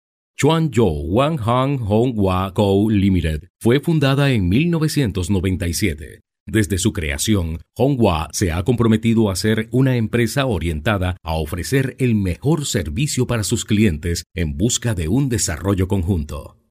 西班牙语样音试听下载